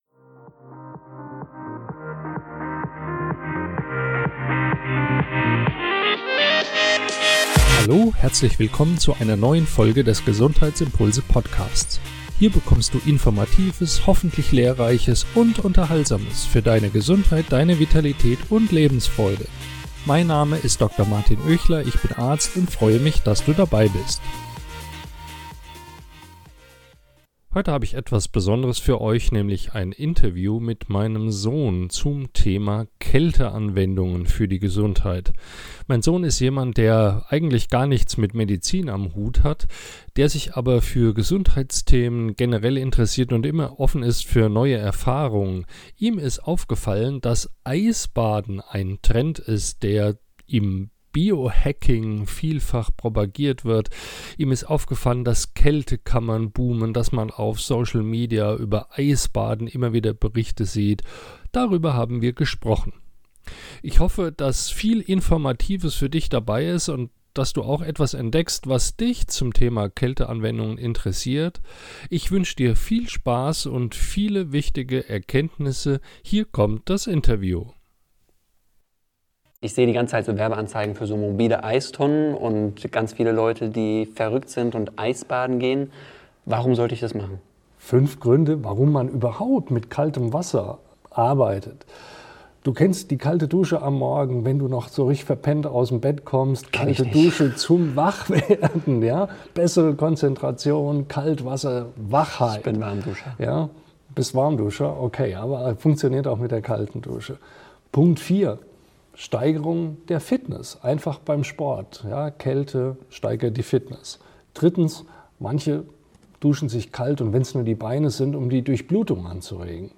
In diesem Interview sprechen wir darüber, wie Kälte nicht nur körperliche Beschwerden lindern kann, sondern auch eine positive Wirkung auf unsere psychische Gesundheit hat.